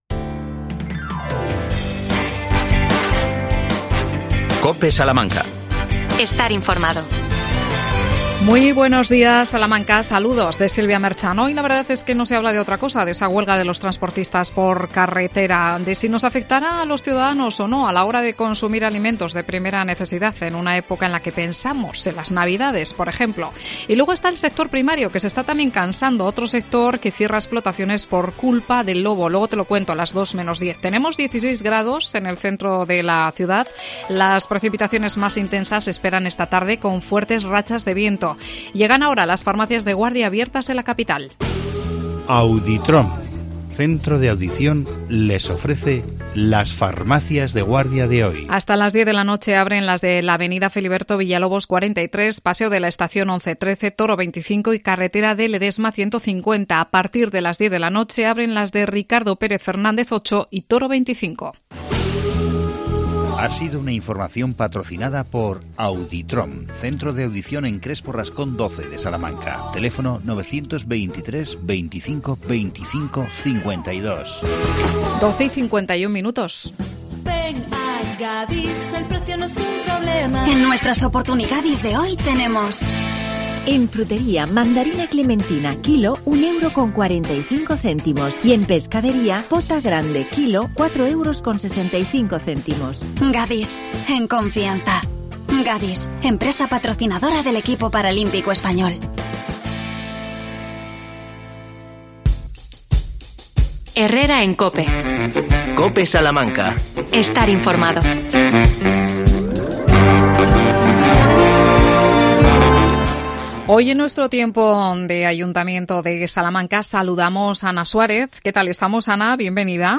AUDIO: Entrevistamos a la concejala Ana Suárez sobre la puesta en marcha del Centro Municipal de Protección Animal.